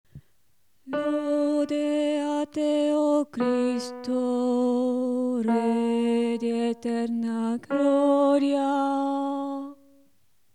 CONTRALTISOPRANI
CDV101-Lode-a-Te-o-Cristo-Contralti-Acclamazione-1^-melodia.mp3